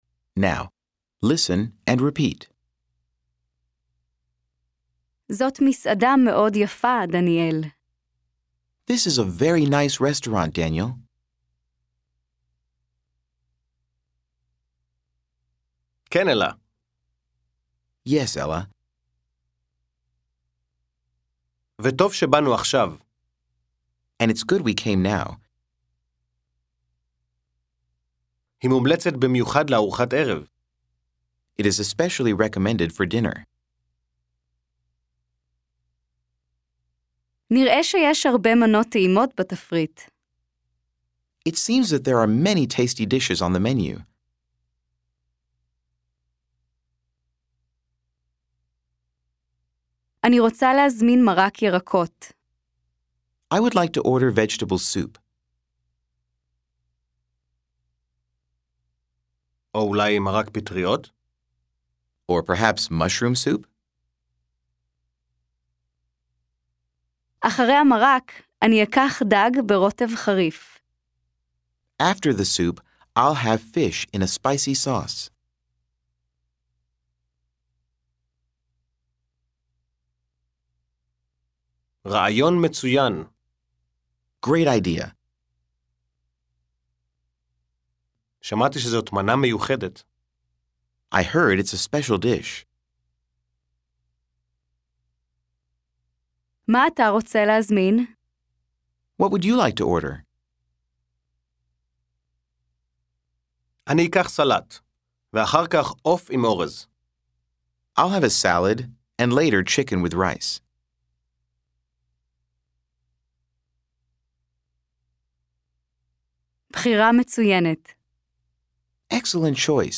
15 Dialogue 3b.mp3